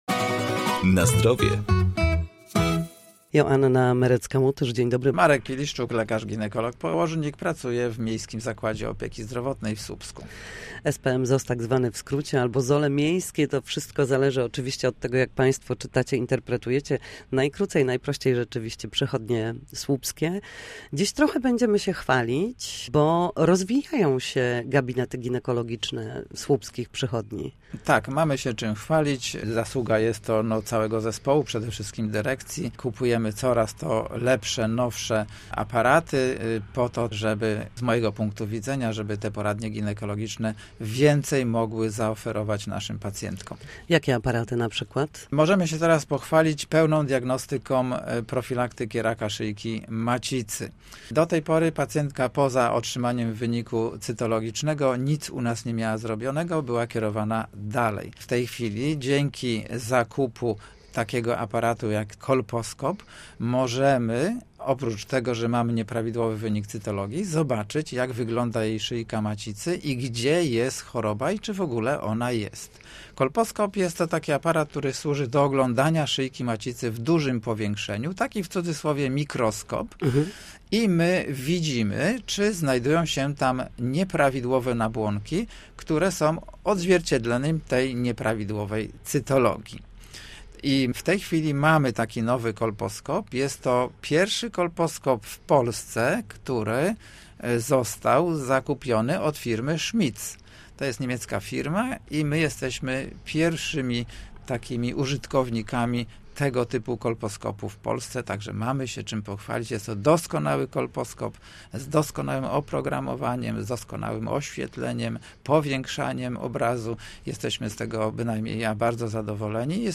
W poniedziałki o godzinach 7:20 i 14:30 na antenie Studia Słupsk dyskutujemy o tym, jak wrócić do formy po chorobach i urazach.